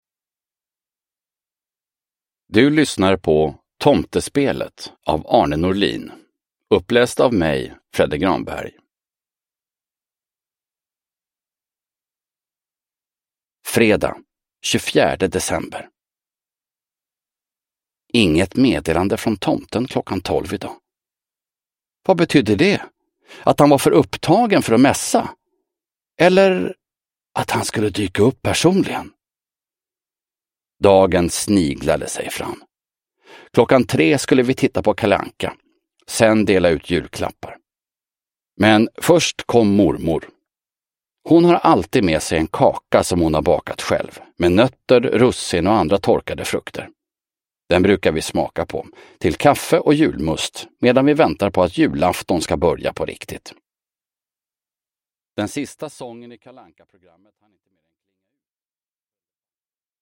Tomtespelet: Julkalender för barn. Lucka 24 – Ljudbok – Laddas ner